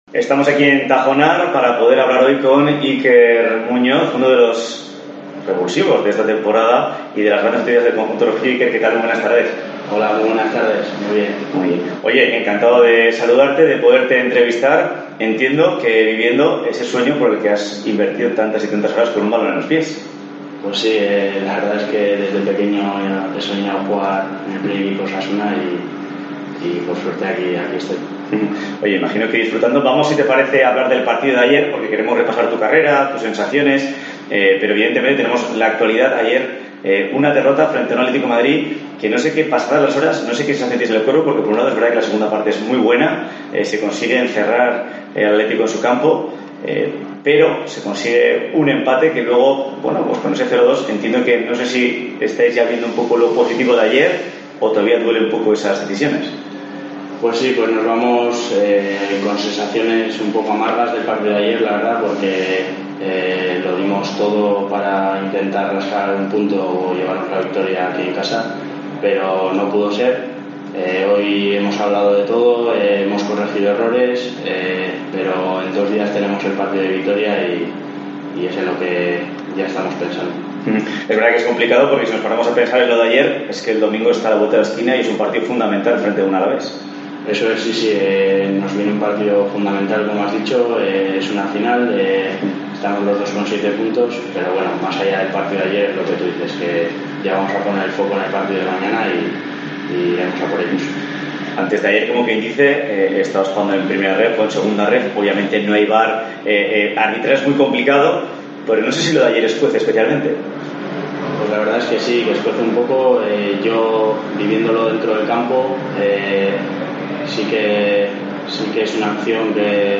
El canterano rojillo recuerda en la entrevista de COPE Navarra sus inicios en las categorías inferiores hasta llegar al primer equipo de Osasuna